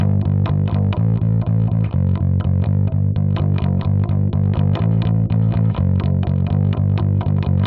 标签： 125 bpm Rock Loops Bass Loops 661.53 KB wav Key : A
声道单声道